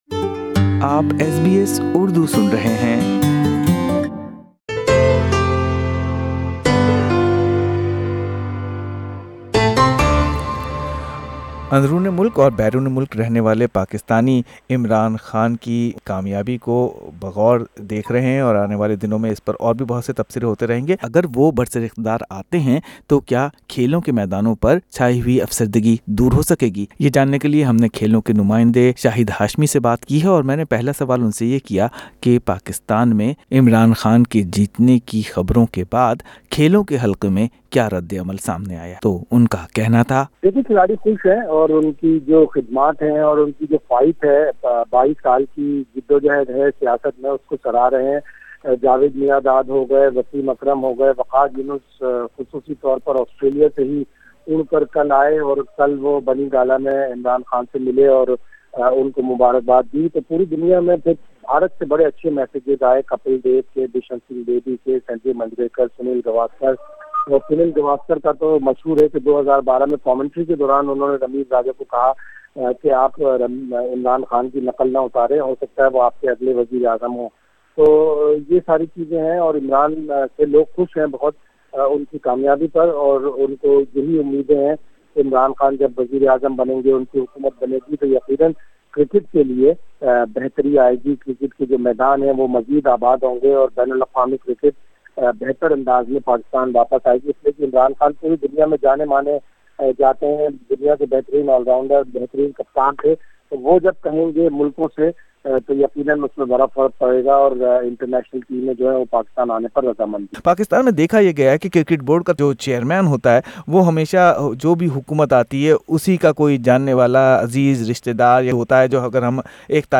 reporting on reactions and expectations.